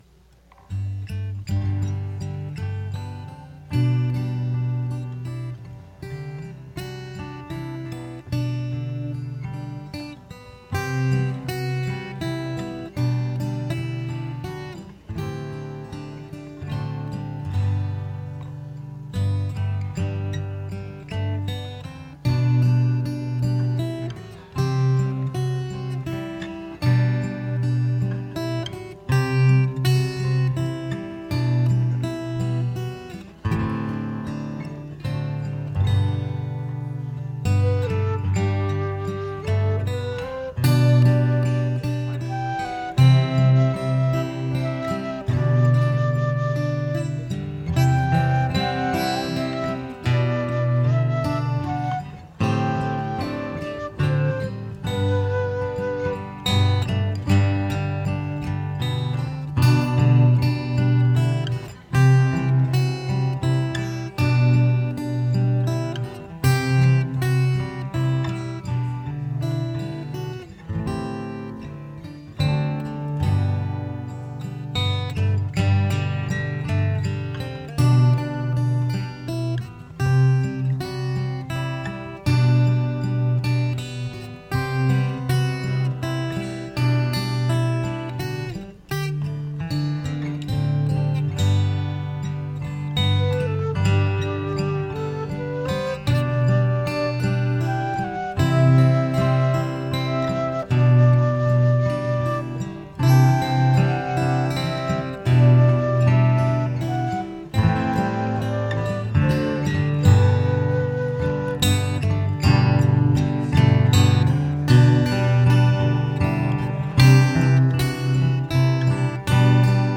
Traditions – Midnight Service